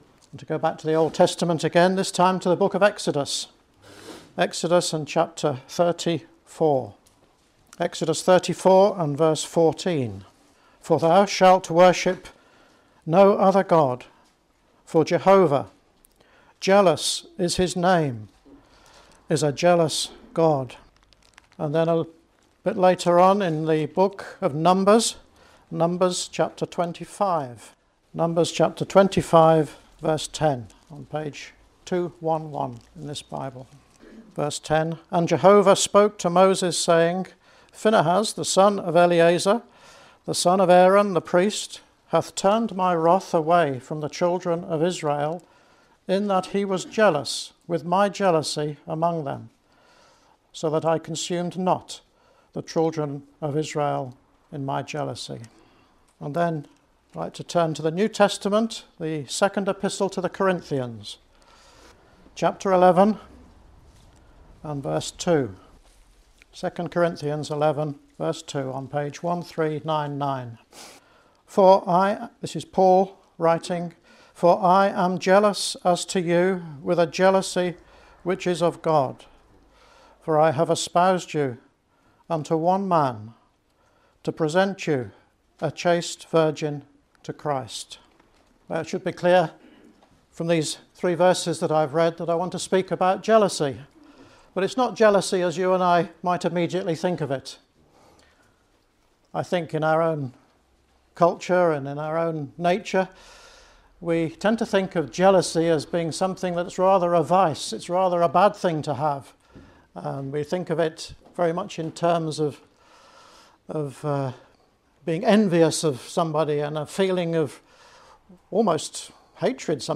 Bible Teaching (Addresses)